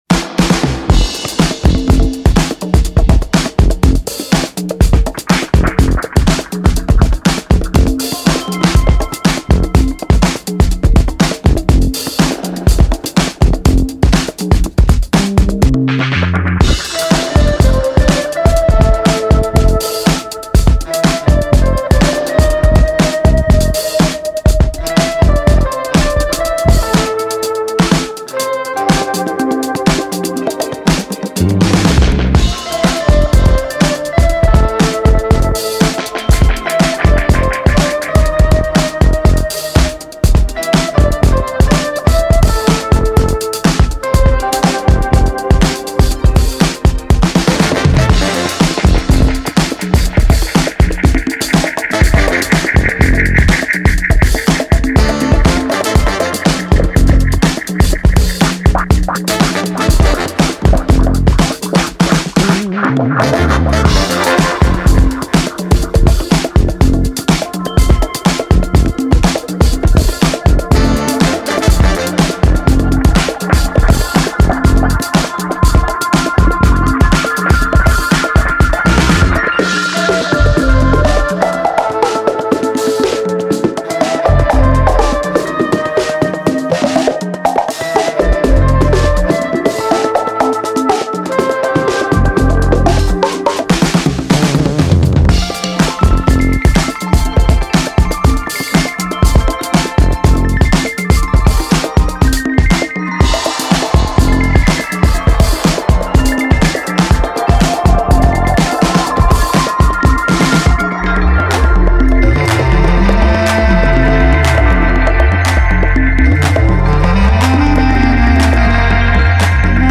tenor and baritone saxes
trumpet
guitar
bass
drums
keys